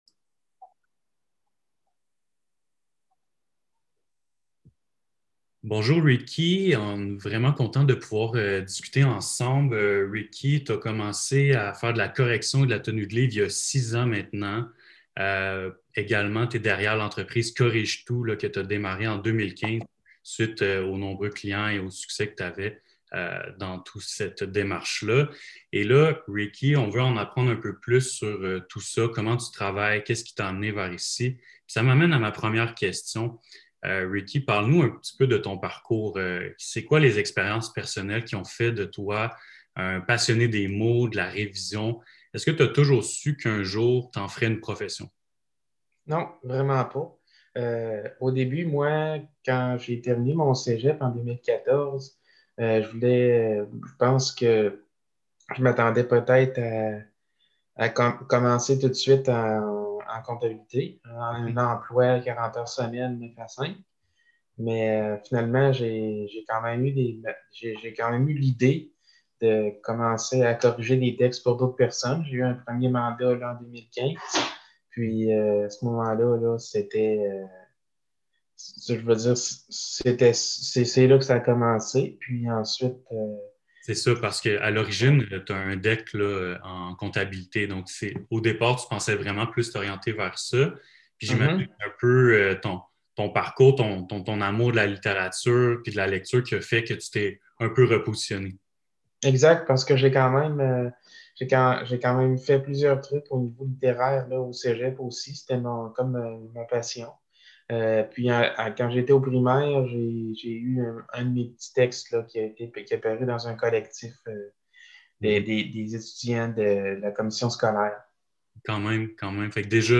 ENTREVUE LUDIQUE, INFORMATIVE MAIS AUSSI DÉCONTRACTÉE